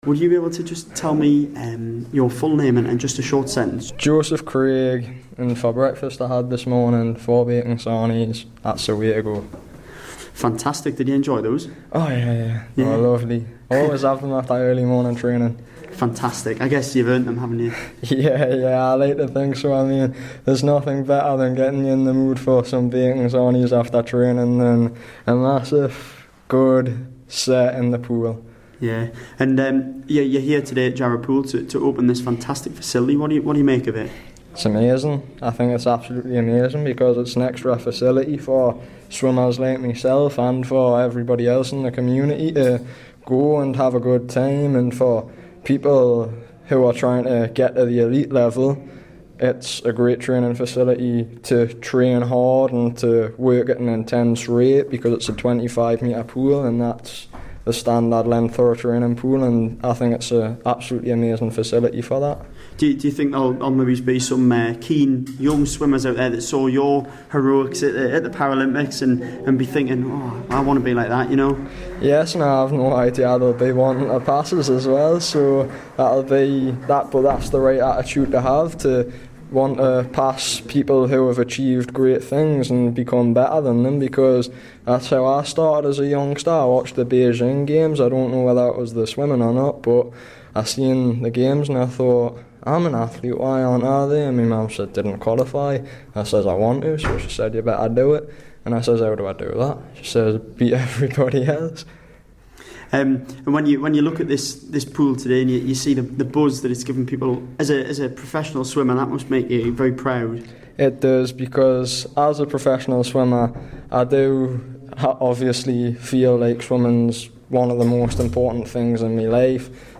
My chat with the gold medal winning Paralympian for Metro Radio News.